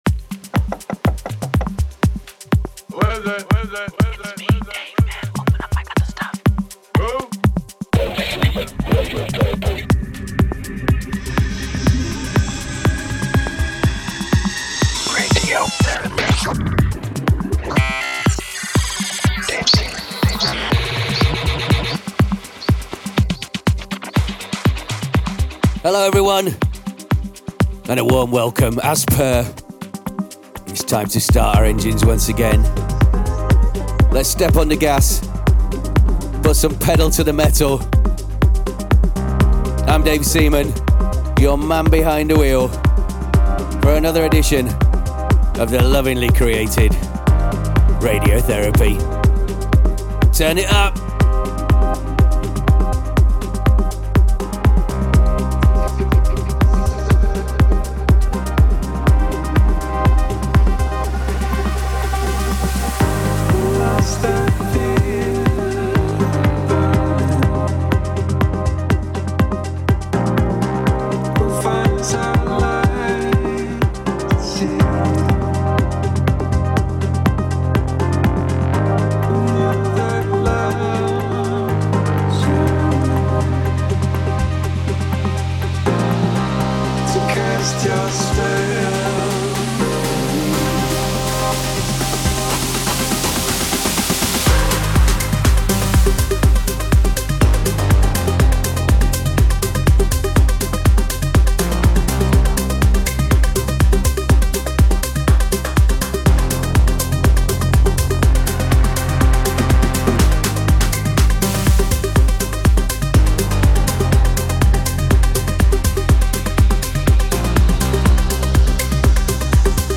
Also find other EDM Livesets, DJ Mixes and